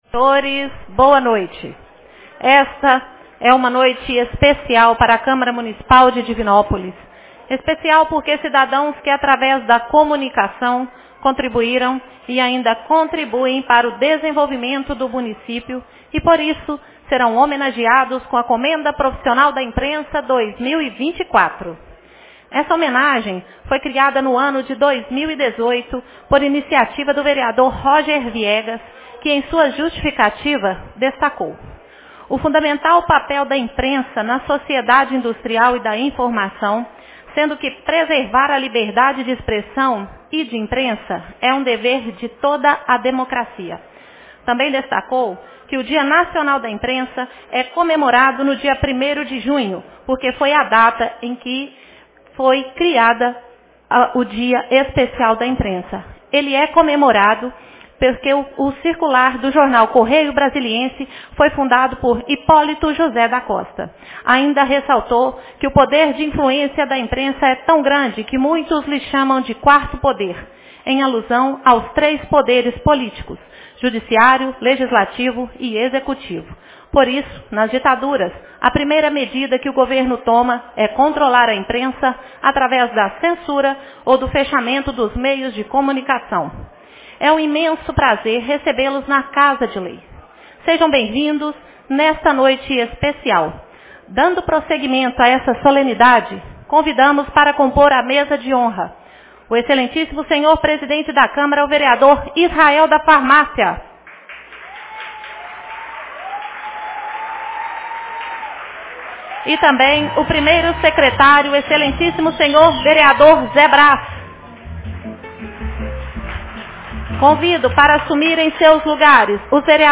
Reuniões Especiais